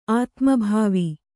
♪ ātmabhāvi